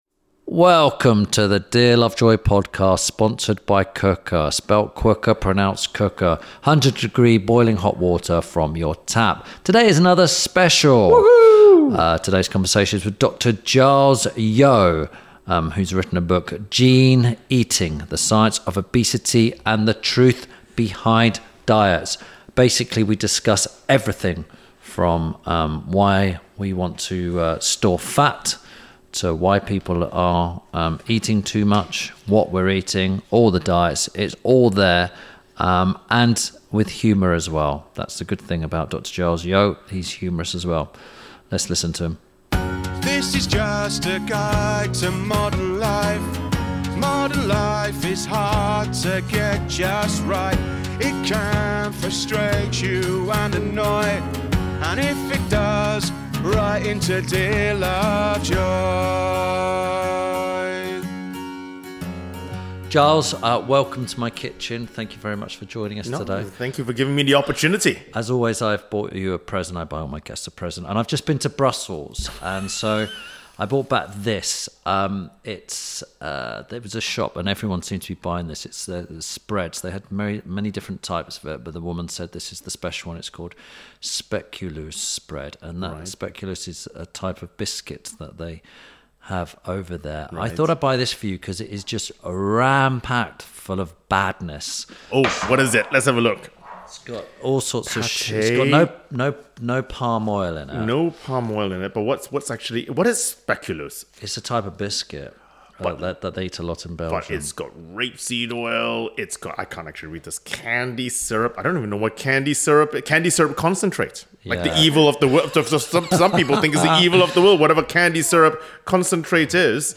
– INTERVIEW SPECIAL
This week Tim Lovejoy talks to geneticist and TV presenter Dr. Giles Yeo. Whilst discussing Giles’ book Gene Eating, they touch on why our bodies need to get fat, what a calorie actually is, and the myths behind diets.